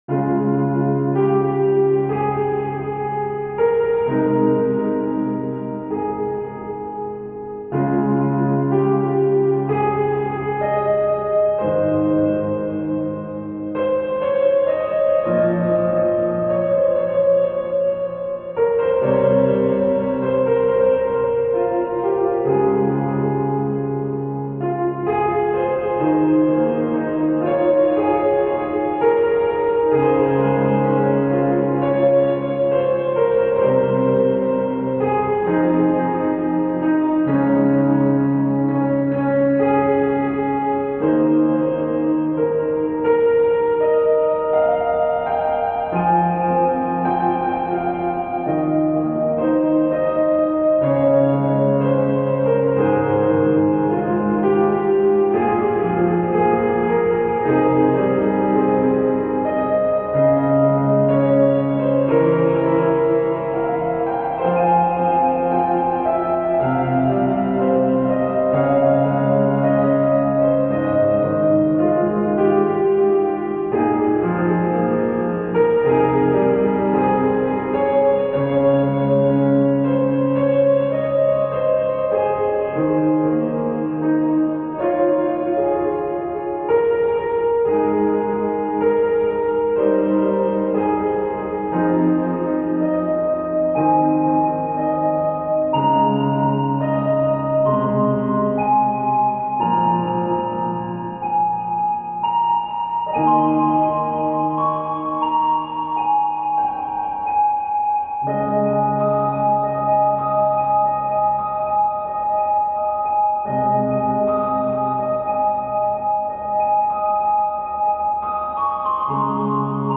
バラード ピアノ 儚い 切ない 懐かしい 穏やか